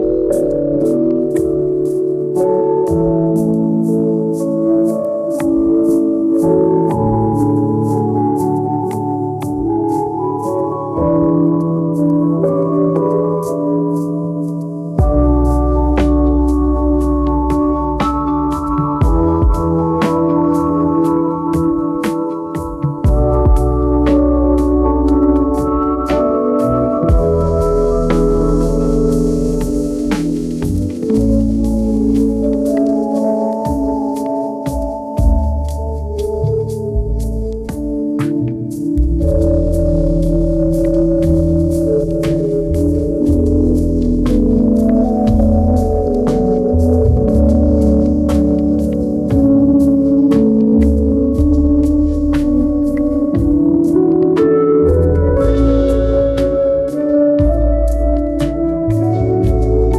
infinifi - infinifi plays gentle lofi music in the background indefinitely